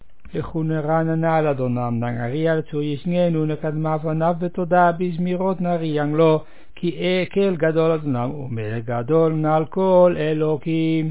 The service at the unveiling of a Matseva (tombstone) at Beth Haim in Ouderkerk aan de Amstel.
In contrast to the mitsva (levaya), there is no singing: all texts are recited.
Recited